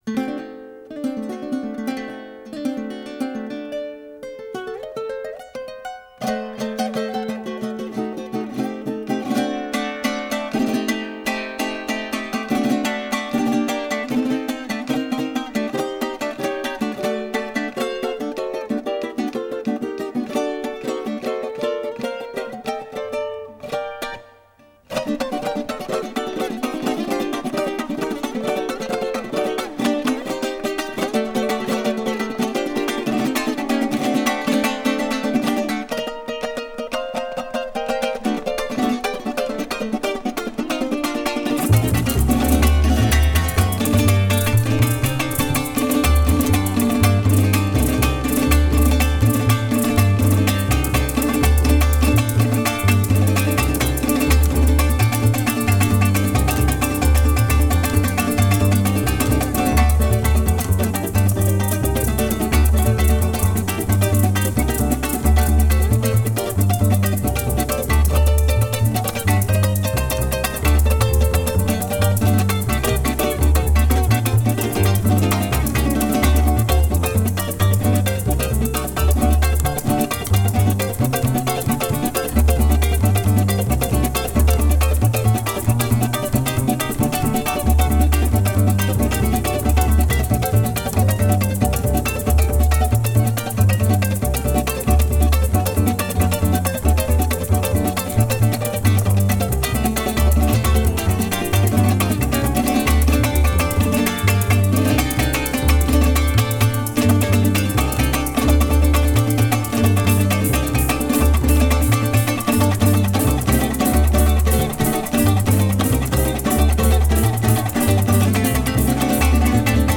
La música de América Latina.